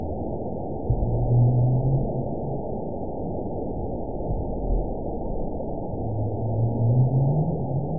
event 916220 date 12/27/22 time 12:38:04 GMT (2 years, 5 months ago) score 8.03 location INACTIVE detected by nrw target species NRW annotations +NRW Spectrogram: Frequency (kHz) vs. Time (s) audio not available .wav